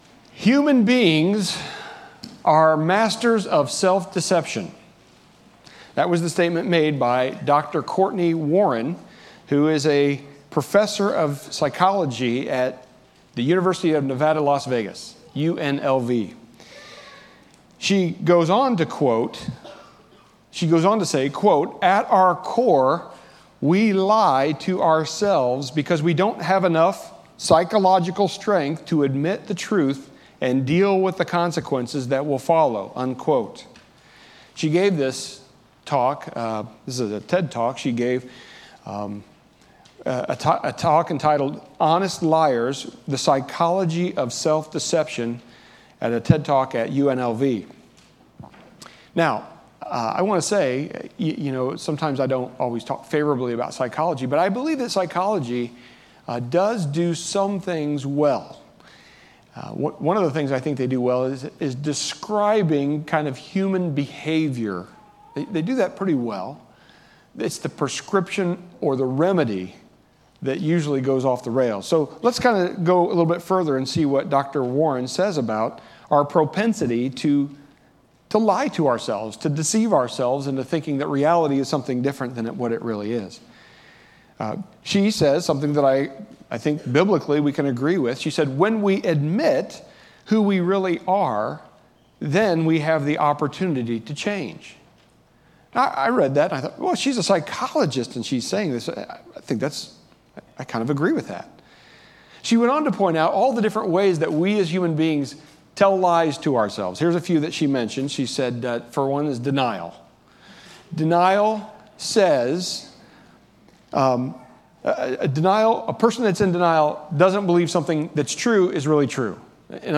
Sermons All sermons are recorded from our Sunday morning worship service unless otherwise noted.